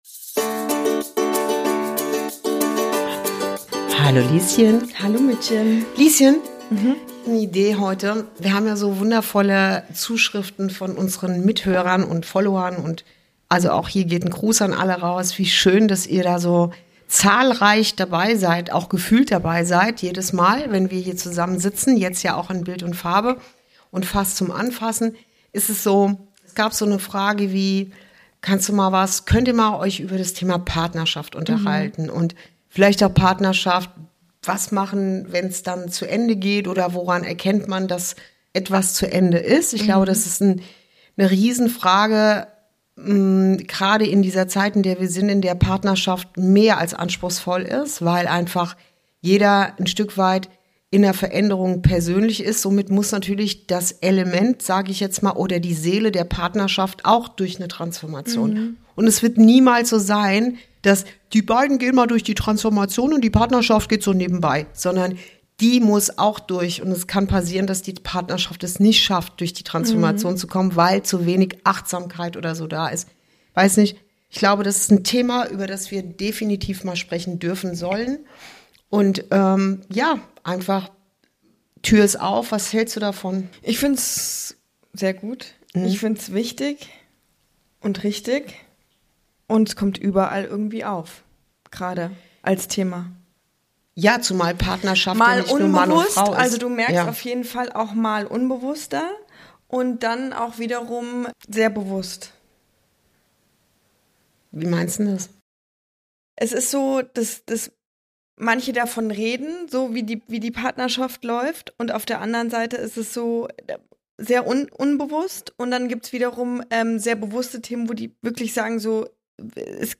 ~ Inside Out - Ein Gespräch zwischen Mutter und Tochter Podcast